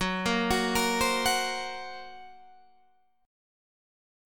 F# Major Flat 5th